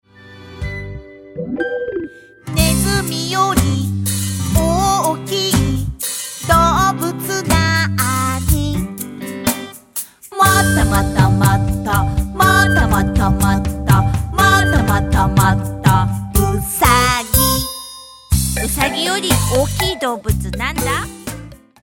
手あそび＆ことばあそび】